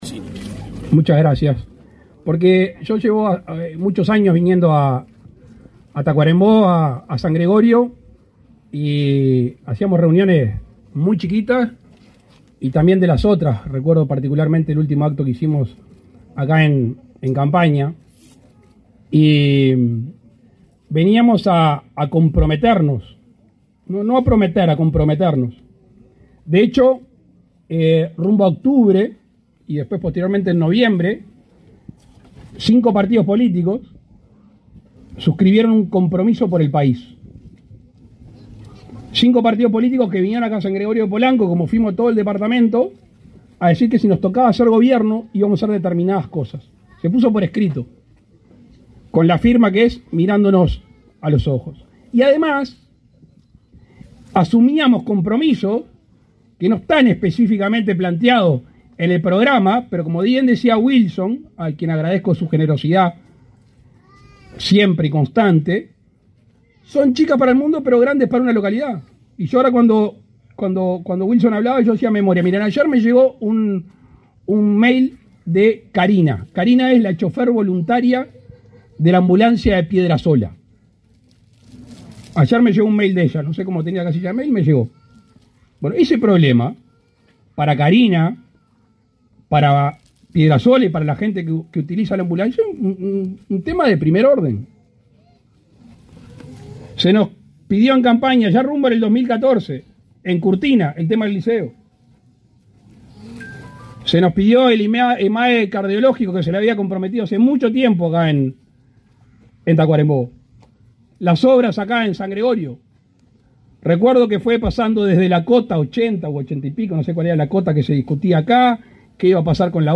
Palabras del presidente de la República, Luis Lacalle Pou, en San Gregorio de Polanco
Palabras del presidente de la República, Luis Lacalle Pou, en San Gregorio de Polanco 31/01/2023 Compartir Facebook X Copiar enlace WhatsApp LinkedIn El presidente de la República, Luis Lacalle Pou, participó, este 30 de enero, en la inauguración de calles e inicio de obras de saneamiento en San Gregorio de Polanco, en el departamento de Tacuarembó.